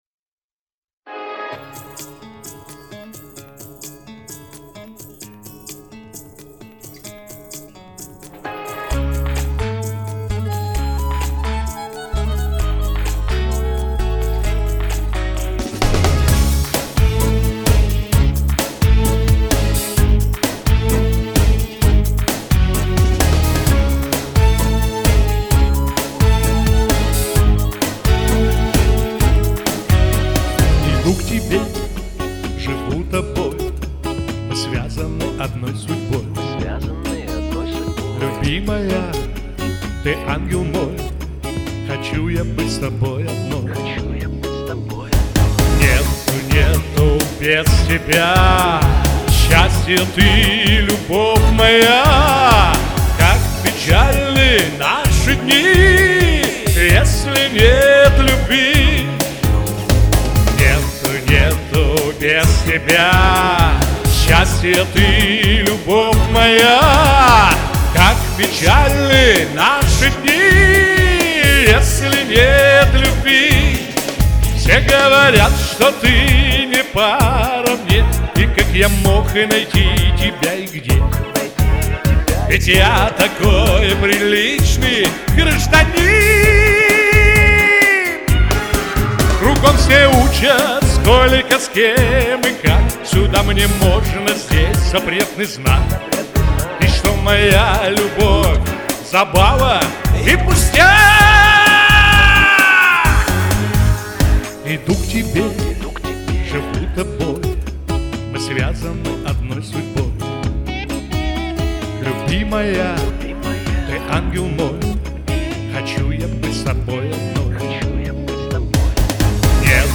Очень кантиленный. Всё время на волне .
Поярче бы само сведение .